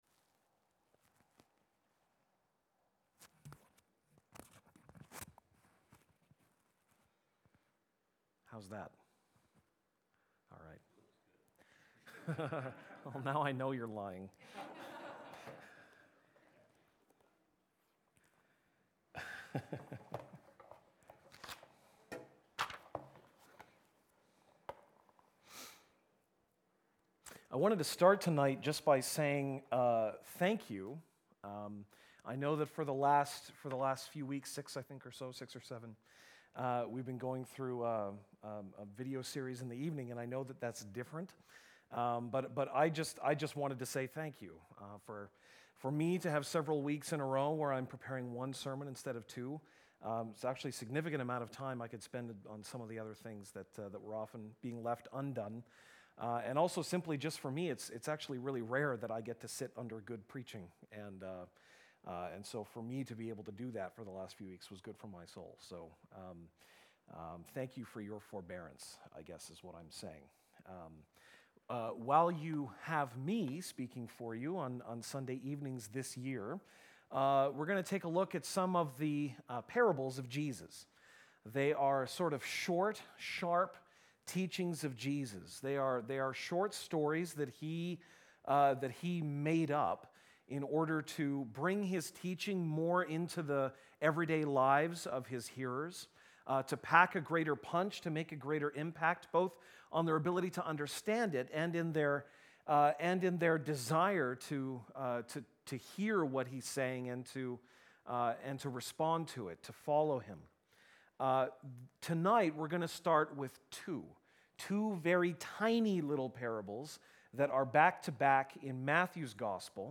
March 17, 2019 (Sunday Evening)